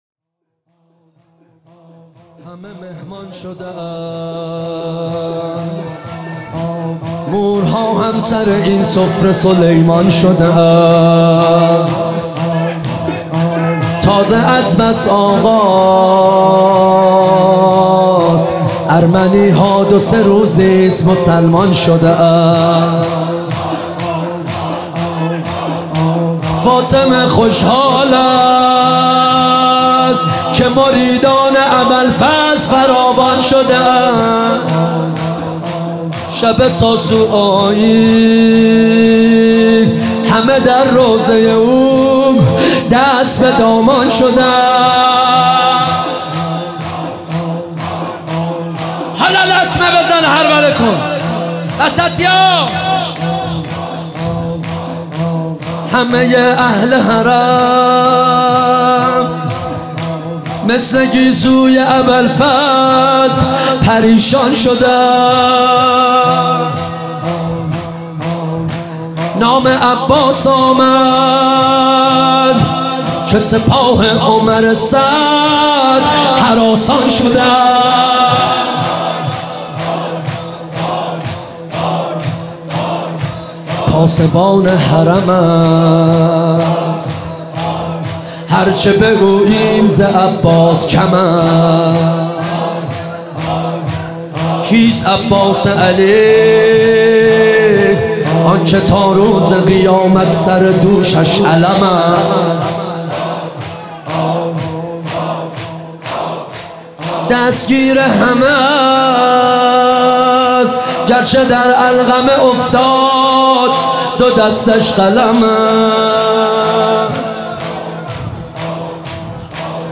مناسبت : شب هشتم محرم
قالب : شور